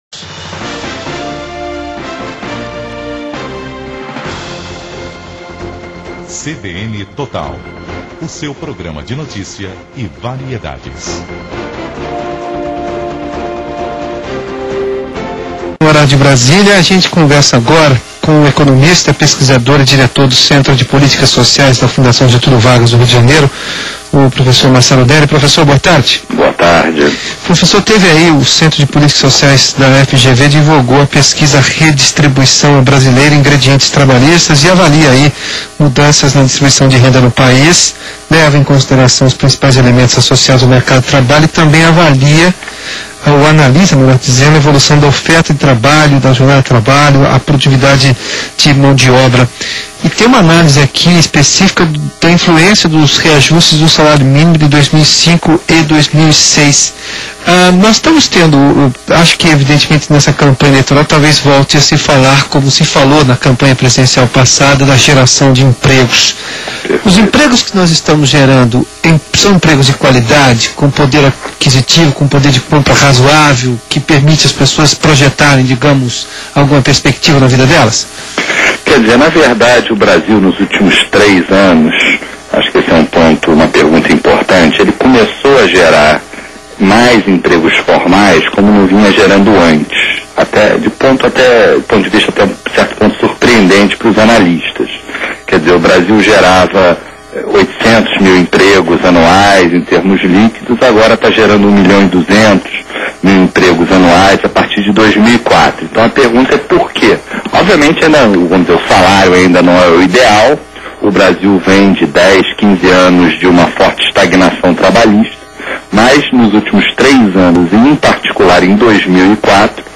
Entrevista
CBN - RJ Mídia: Rádio